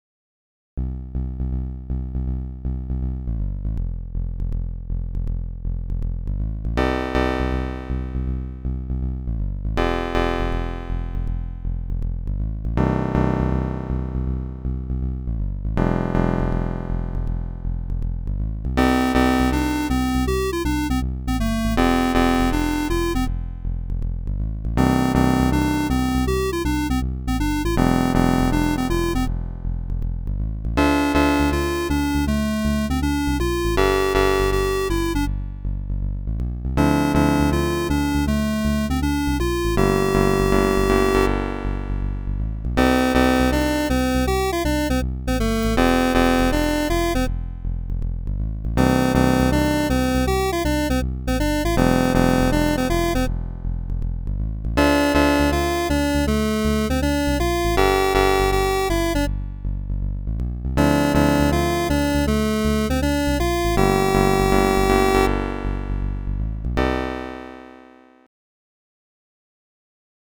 Early Synth-Wave Experiments